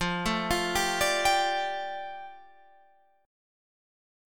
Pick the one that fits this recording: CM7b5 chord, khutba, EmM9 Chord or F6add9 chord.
F6add9 chord